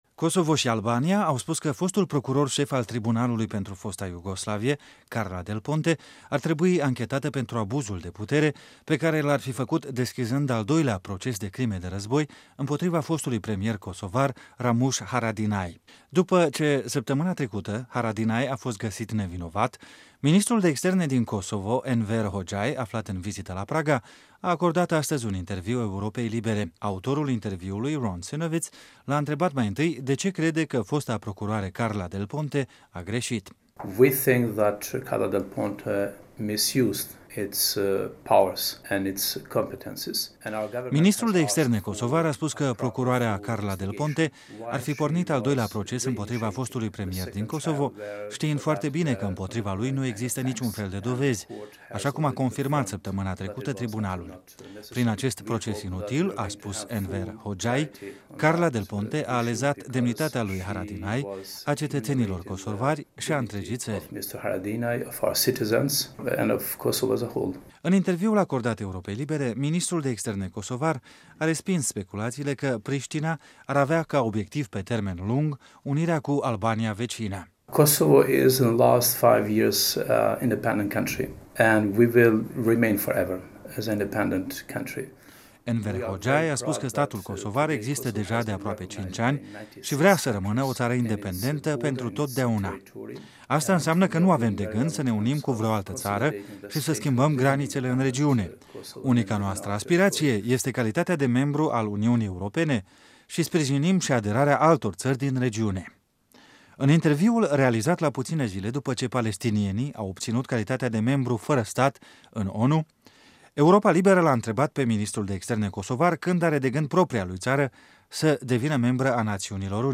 Un interviu cu ministrul kosovar de externe Enver Hoxhaj